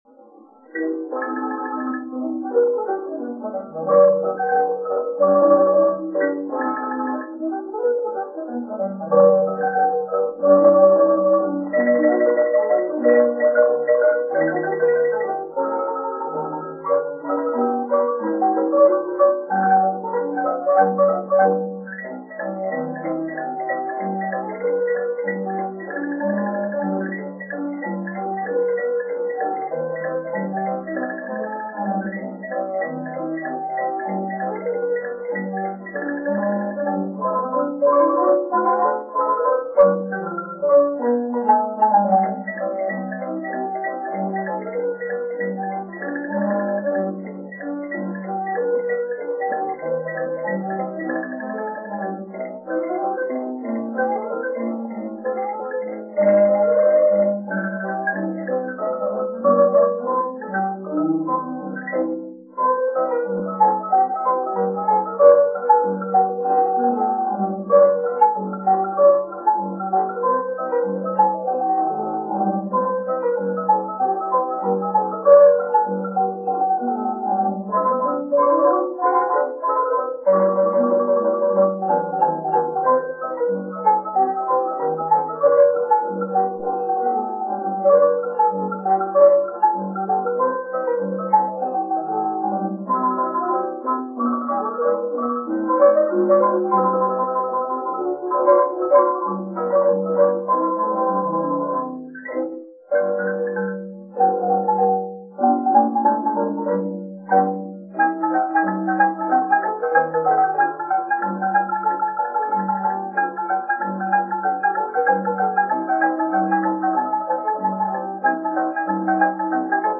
The song you'll hear playing below was recorded in 1921 by the Imperial Marimba Band.
Edison Cylinder Phonograph